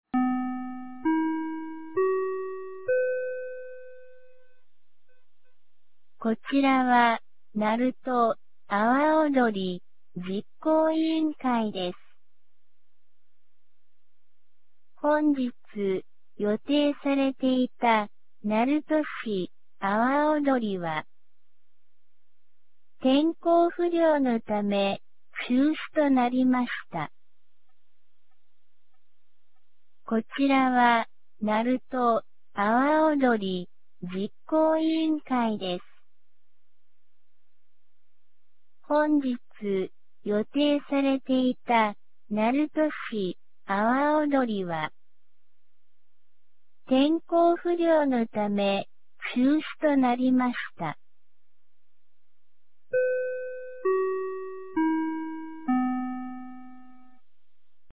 2025年08月10日 17時32分に、鳴門市より全地区へ放送がありました。